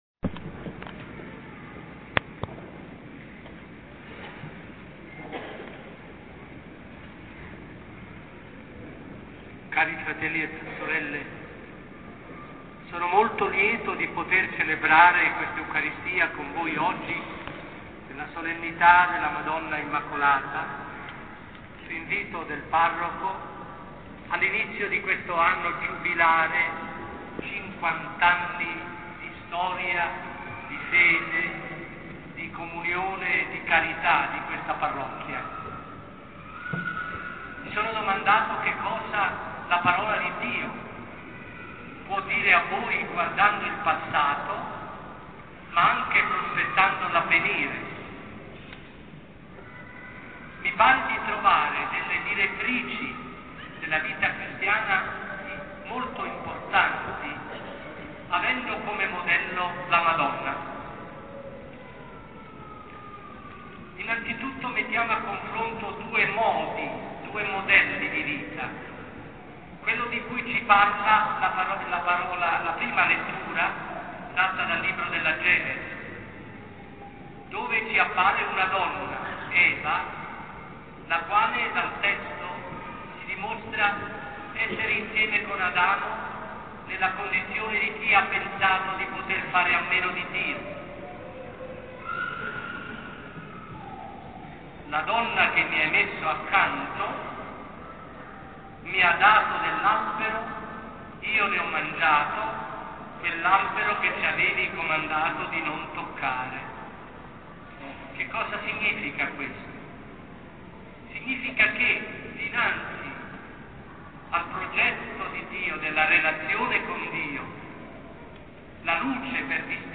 [ascolta l' omelia del Cardinale]
omeliavallini.mp3